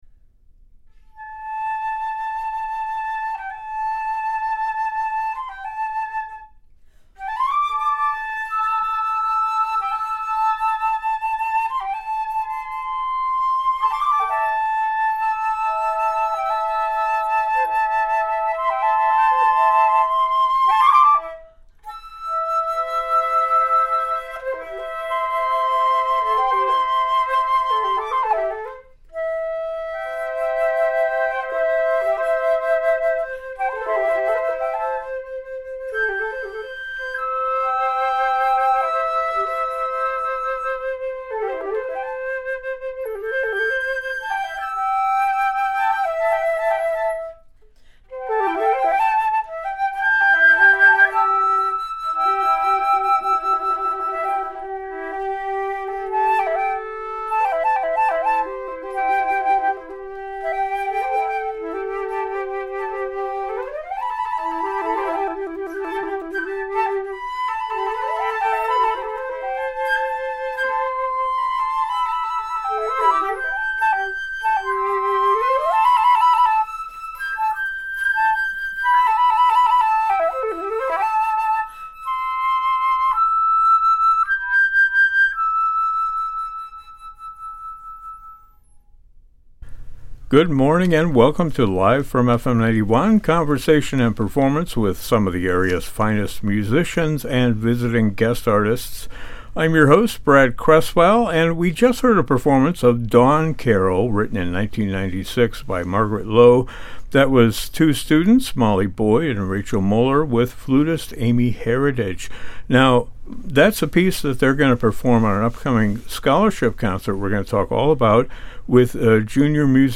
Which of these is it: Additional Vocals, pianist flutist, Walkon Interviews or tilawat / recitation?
pianist flutist